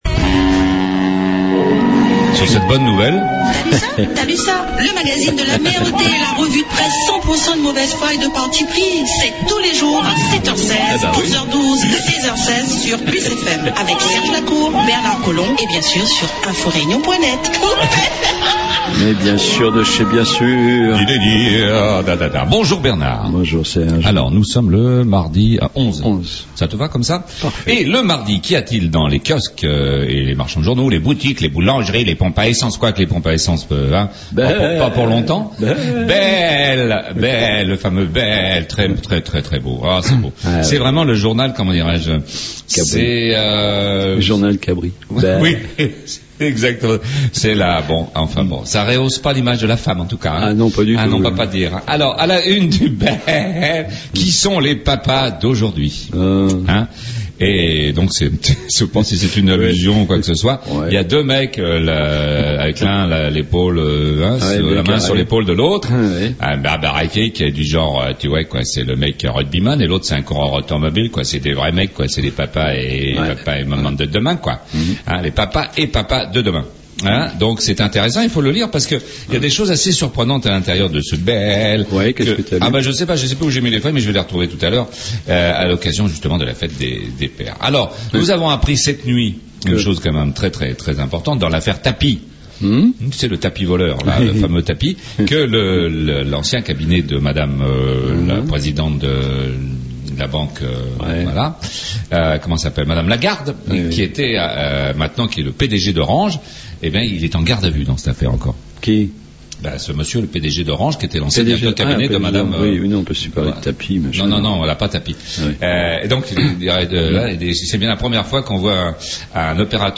La revue de presse du jour